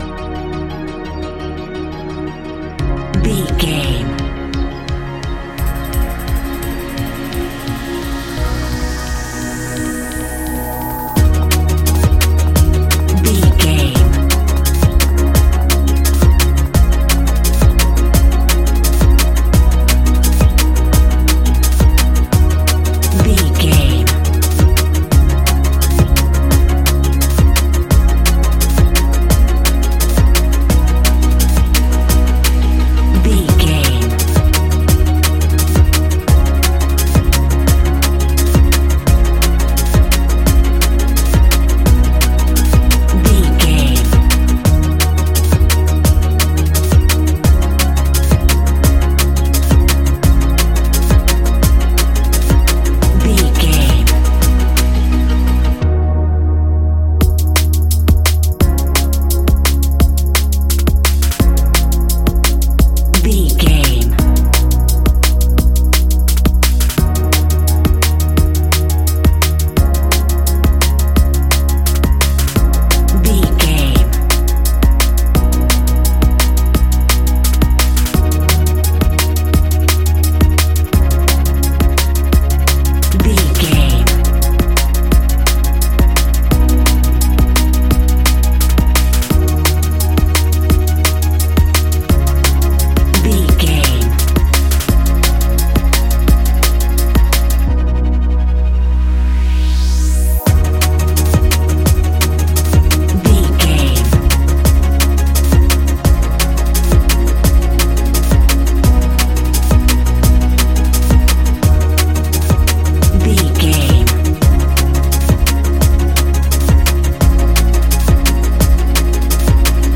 Ionian/Major
electronic
dance
techno
trance
synths
synthwave